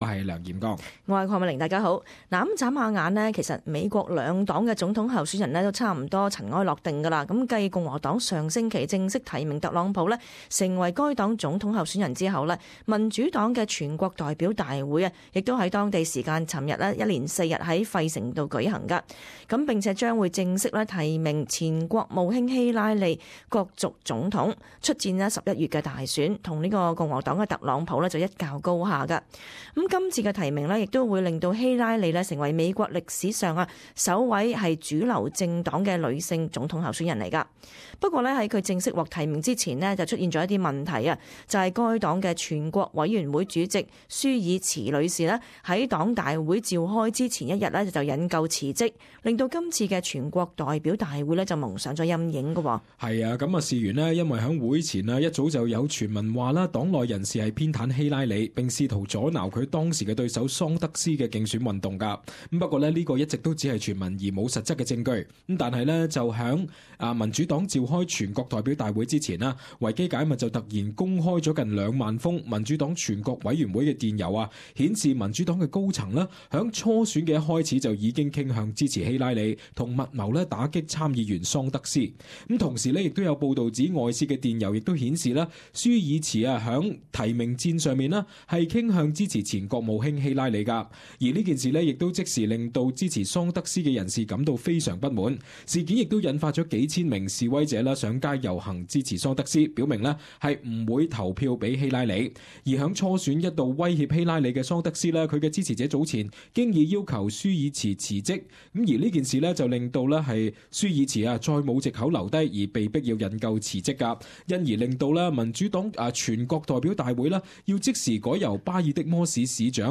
【時事報道】美國民主黨電郵外洩釀分裂